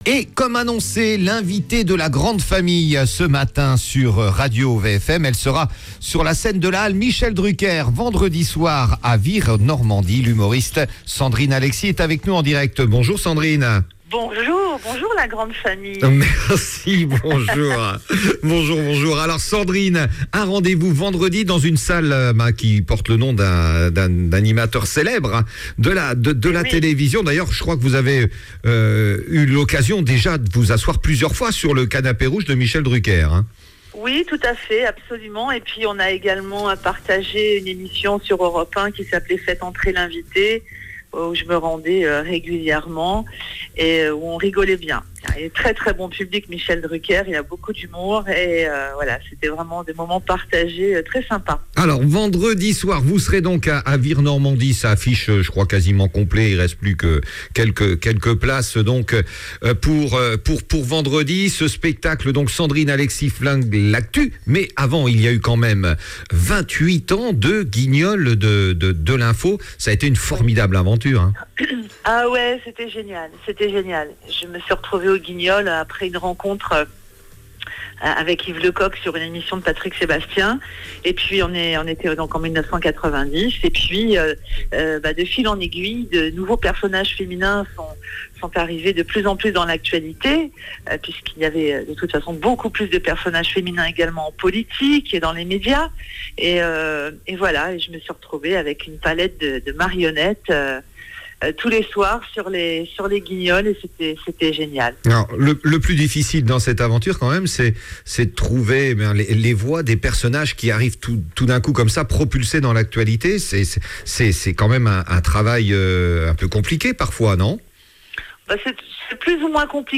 Interview Sandrine Alexi